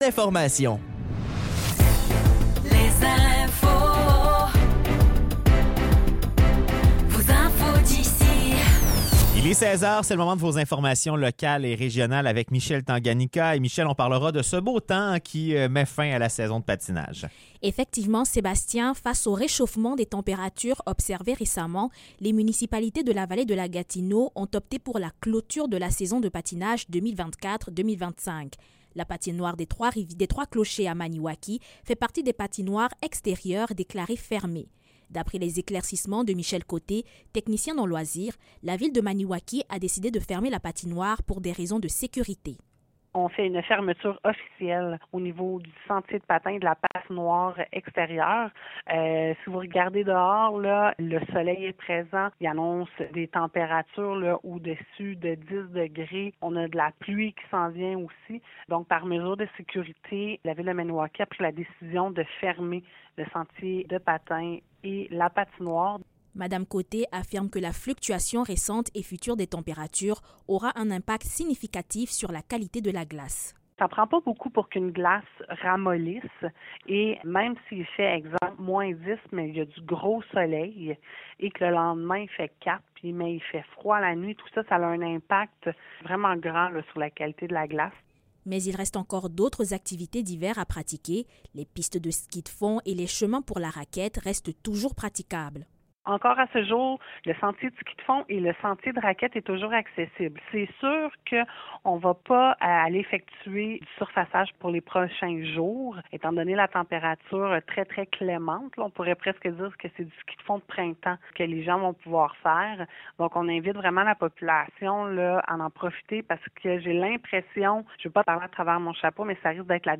Nouvelles locales - 13 mars 2025 - 16 h